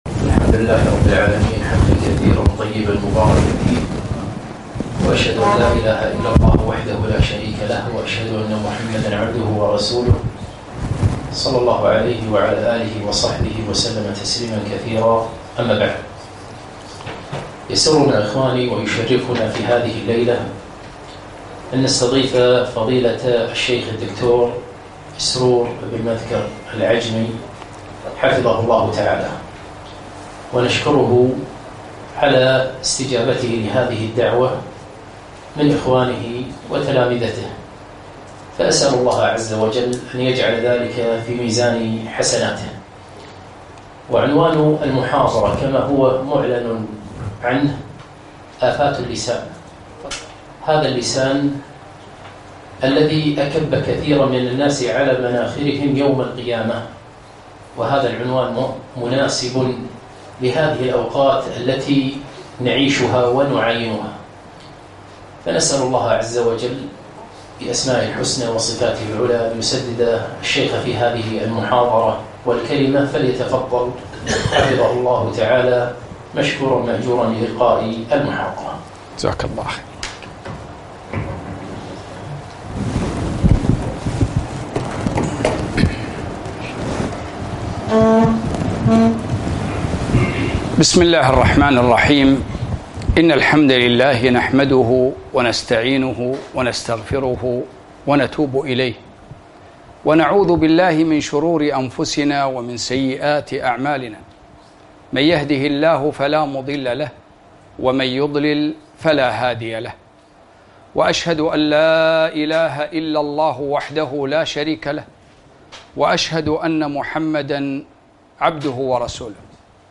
محاضرة - آفات اللسان